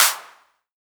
MZ Clap [Sevn #2].wav